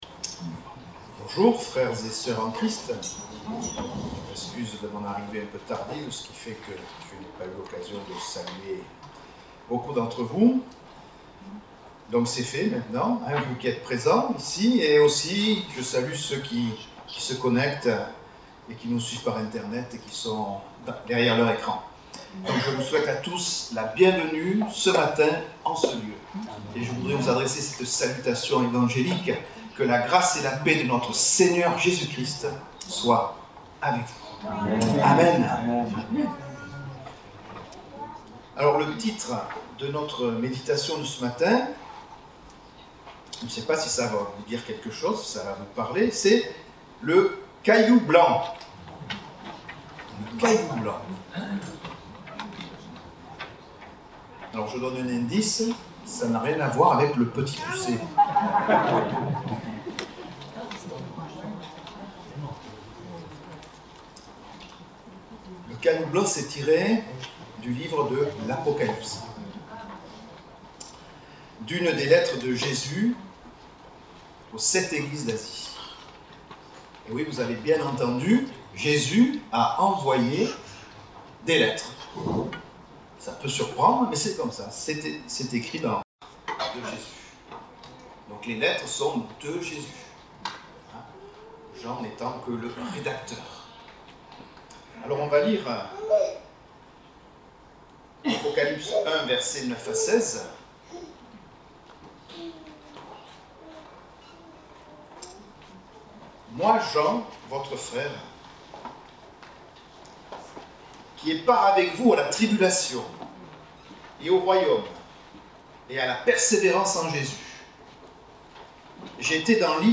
Date : 14 août 2022 (Culte Dominical)